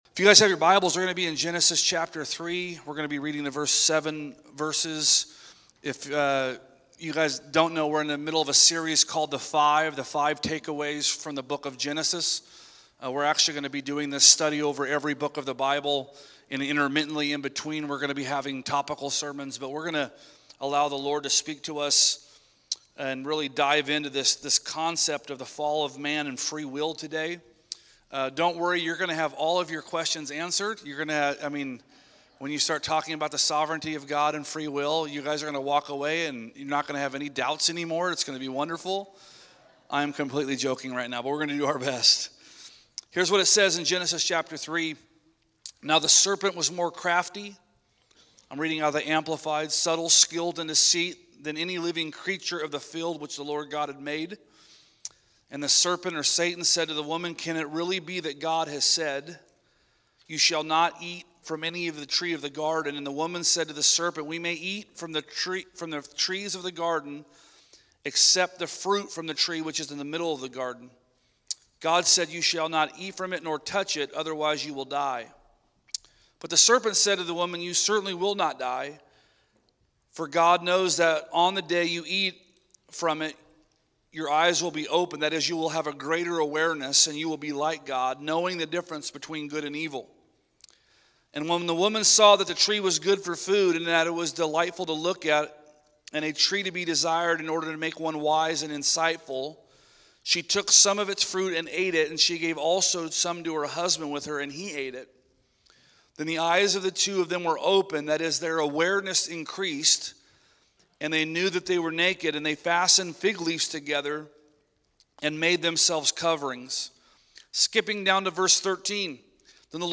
A message from the series "The Five: Genesis." Faith is the substance of belief that the God who made a way for me already, is making a way for me to become the